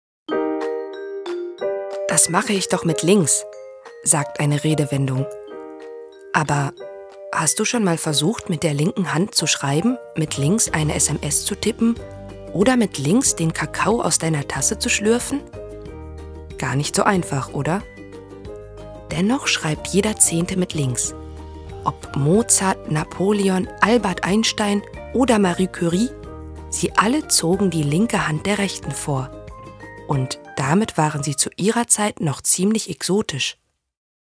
markant
Mittel minus (25-45)
Ruhrgebiet, Berlinerisch
Russian, Eastern European